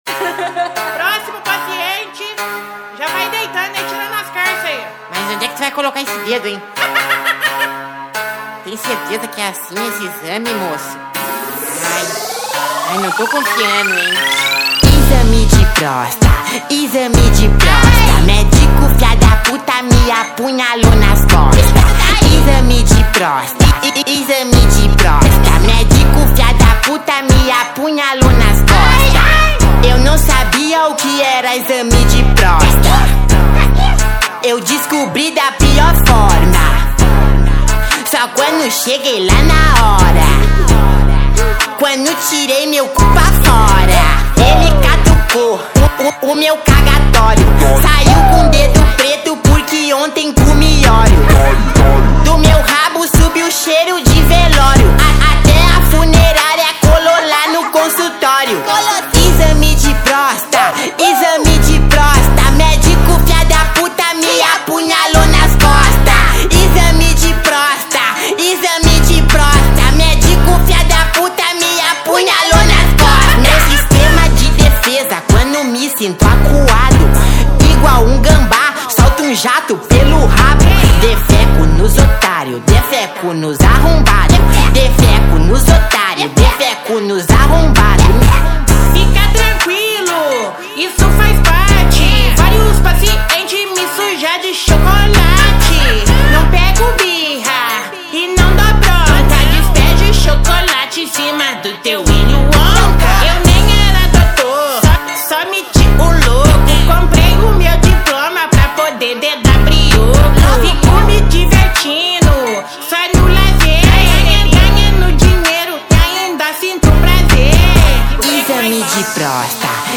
2025-04-17 22:43:16 Gênero: Funk Views